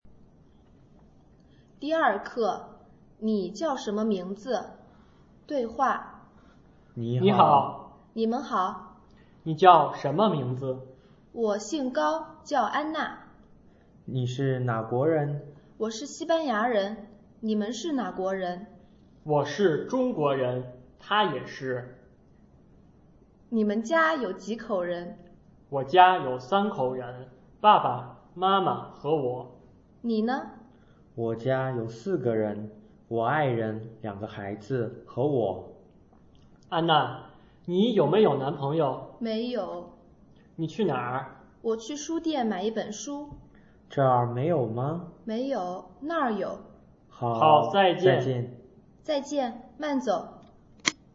Lengua China para traductores - Diálogo Lección 2